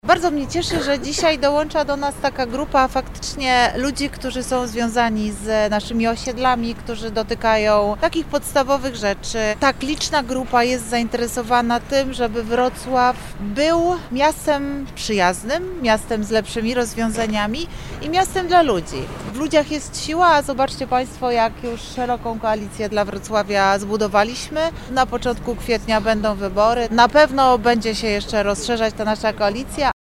Projekt Platformy będzie się rozrastał, podkreśla wiceprezydent Wrocławia Renata Granowska.